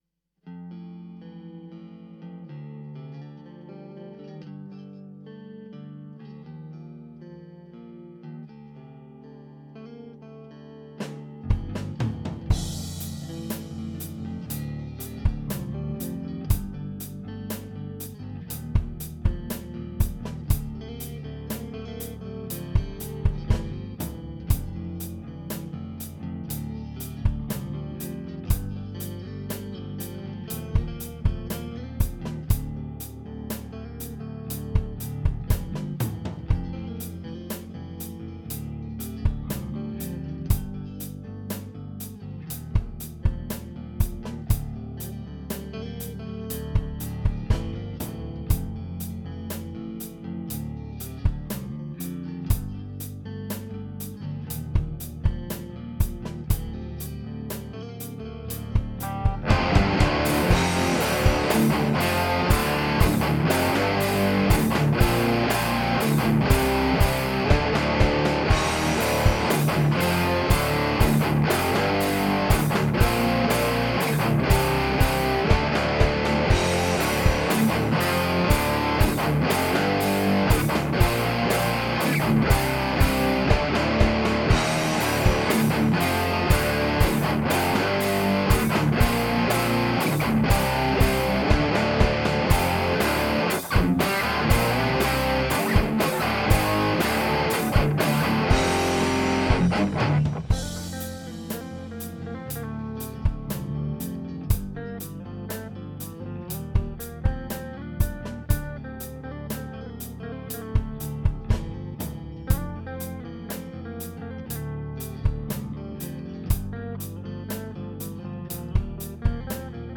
Test Mix
Weird, sounds totally different on phone to computer and iPad.